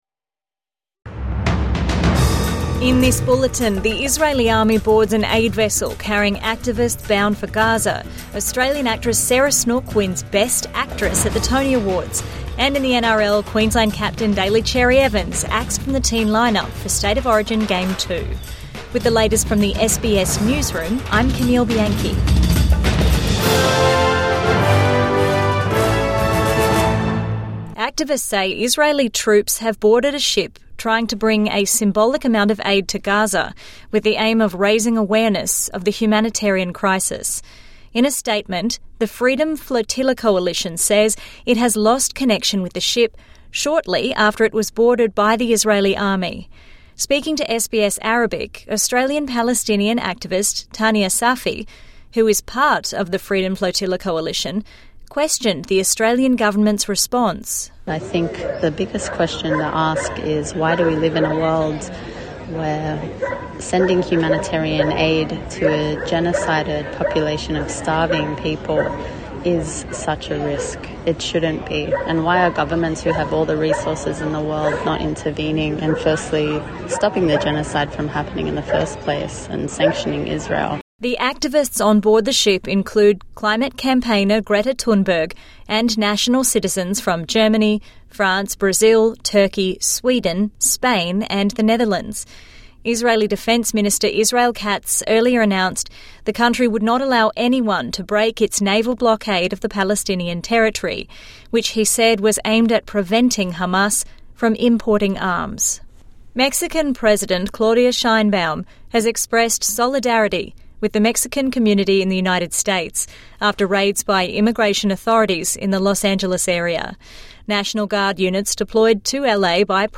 Israeli army boards activist aid ship | Midday News Bulletin 9 June 2025